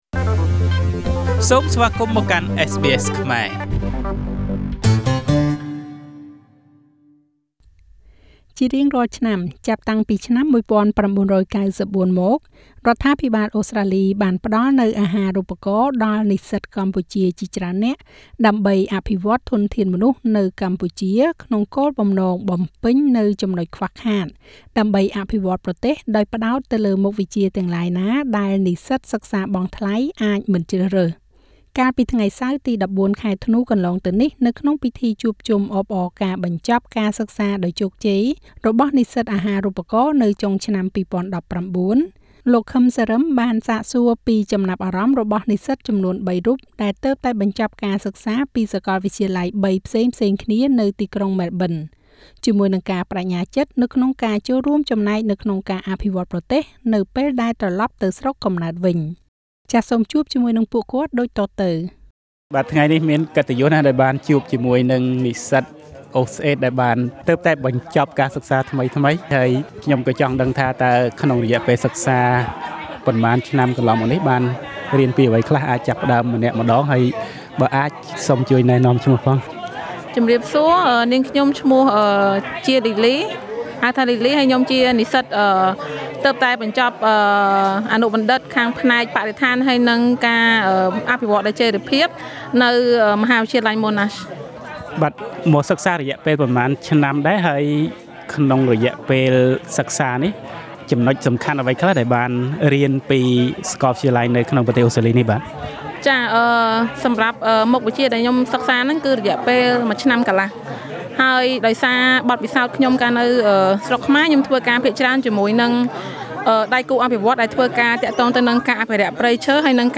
កាលពីថ្ងៃសៅរ៍ ទី១៤ ខែធ្នូ កន្លងទៅនេះ នៅក្នុងពិធីជួបជុំអបអរការបញ្ចប់ការសិក្សាដោយជោគជ័យរបស់និស្សិតអាហារូបករណ៍នៅចុងឆ្នាំ 2019 នេះ SBSខ្មែរបានសាកសួរពី ចំណាប់អារម្មណ៍ របស់និស្សិតចំនួនបីរូប ទើបបញ្ចប់ការសិក្សាពីសាកលវិទ្យាល័យបីផ្សេងៗគ្នានាទីក្រុងម៉ែលប៊ិន ព្រមទាំងការប្តេជ្ញាចិត្តក្នុងការរួមចំណែកក្នុងការអភិវឌ្ឍន៍ប្រទេសពេលត្រឡប់ទៅស្រុកកំណត់វិញ។